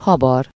Ääntäminen
Vaihtoehtoiset kirjoitusmuodot (vanhentunut) stur Synonyymit stimulate animate incite rouse excite awaken move stir up rollercoaster spur on tintillate stooshie Ääntäminen US UK : IPA : /stɜː/ US : IPA : /stɚ/